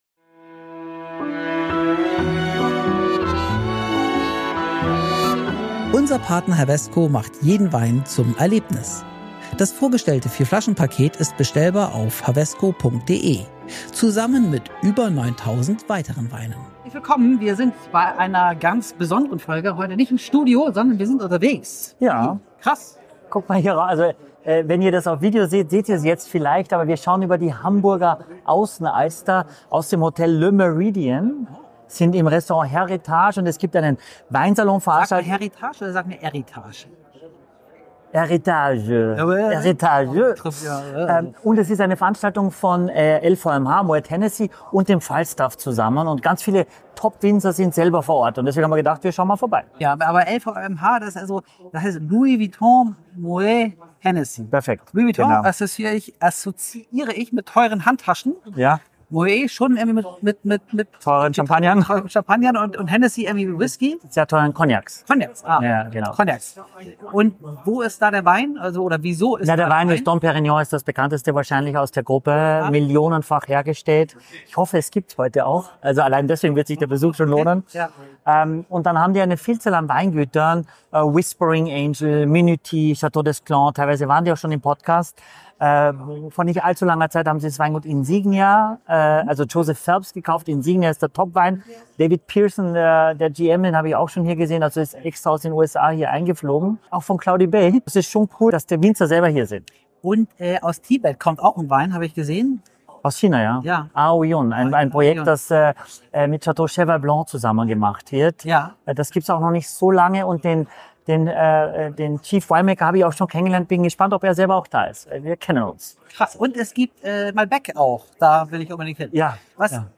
Beim Weinsalon im Hamburger Le Meridien-Hotel präsentierte Louis Vuitton Moet Hennessy (LVMH) zusammen mit dem Falstaff seine Spitzenweine.